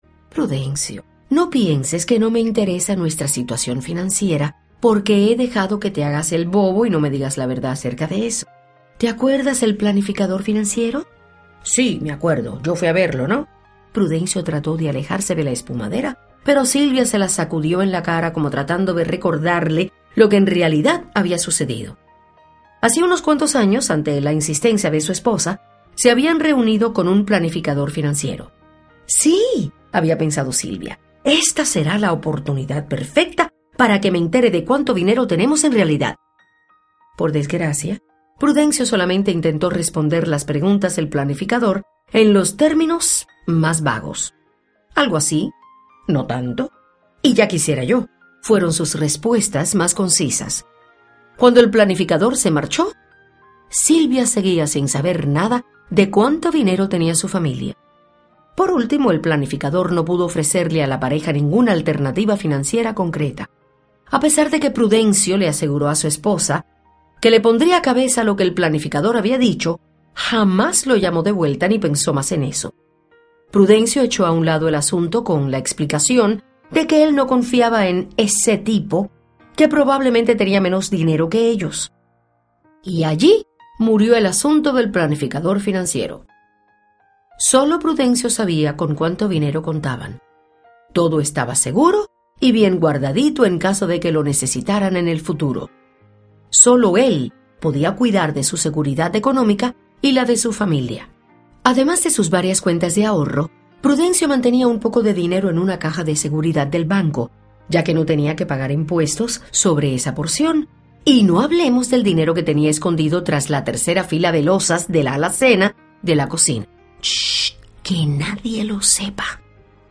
Audio Libros , Inteligencia Financiera